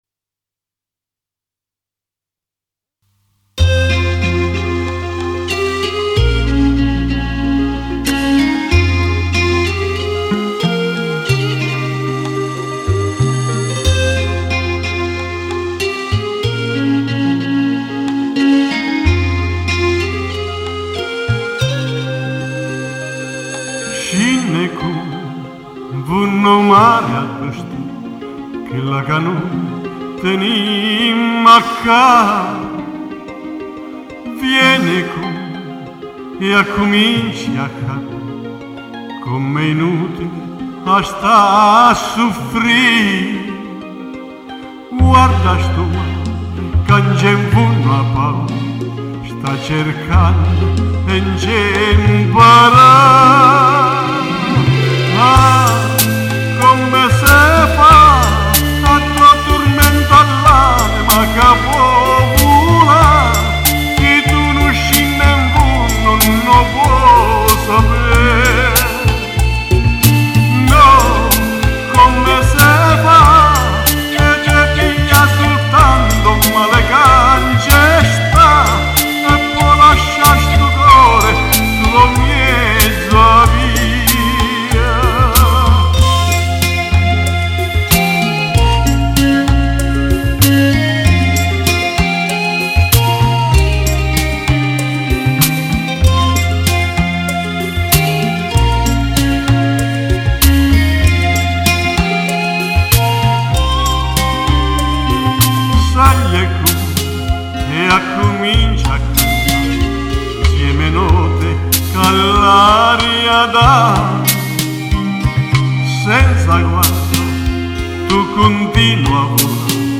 ASCOLTA ALCUNI BRANI CANTATI